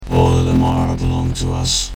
Here’s a soundbyte to get started with. It lacks rhythm. In fact, it lacks any musical qualities, but there are some stunning compositions which have started with less.